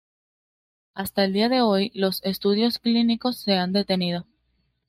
de‧te‧ni‧do
/deteˈnido/